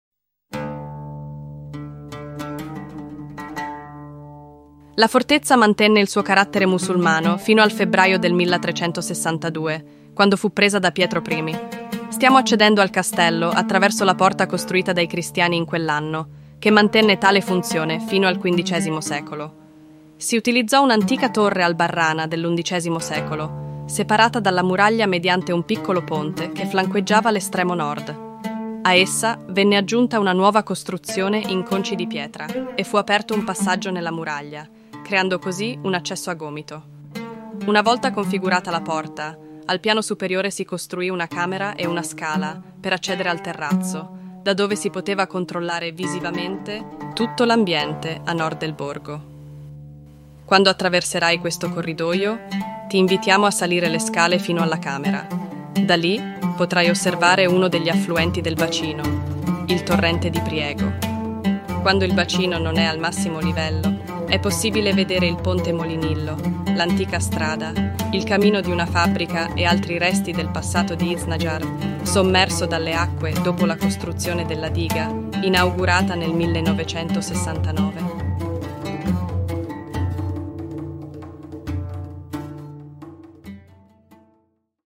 Ruta audioguiada
audioguia-italiano-qr3.mp3